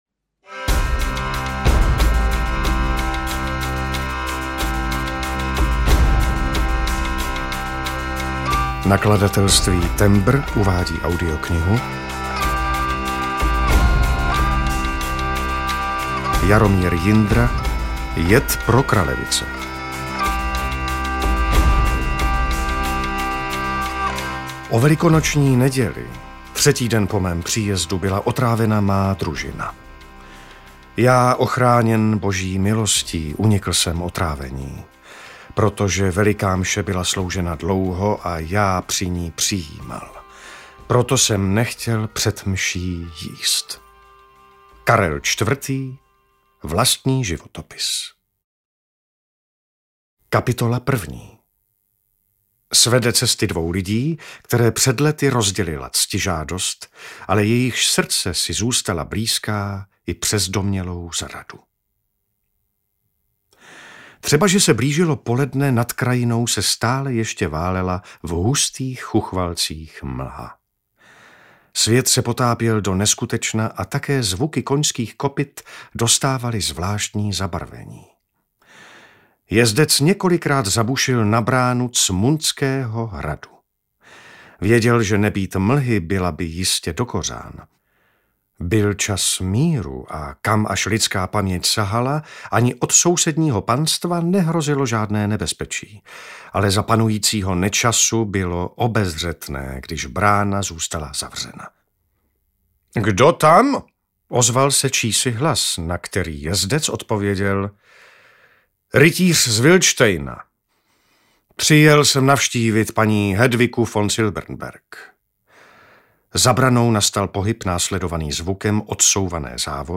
Jed pro kralevice audiokniha
Ukázka z knihy